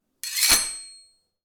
SwordSoundPack
SWORD_23.wav